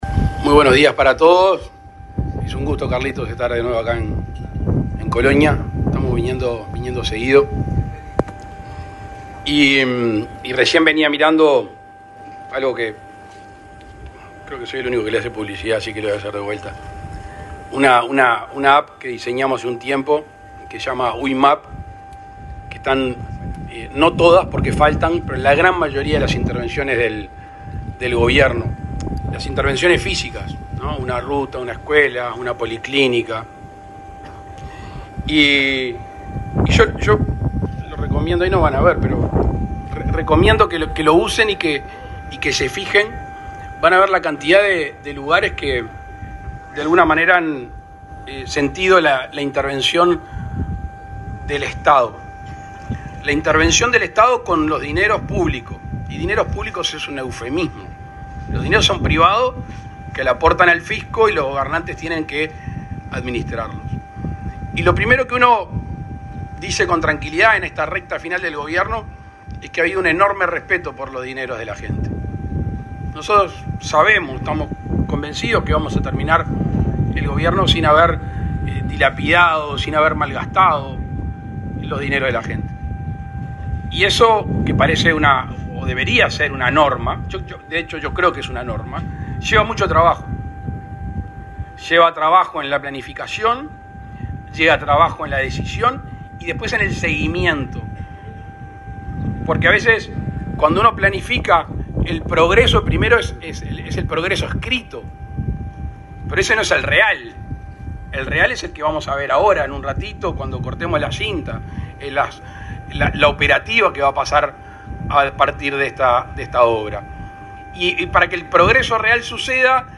Palabras del presidente Luis Lacalle Pou
Este miércoles 16 en Colonia, el presidente Luis Lacalle Pou, participó del acto de inauguración de las nuevas obras en la terminal de pasajeros del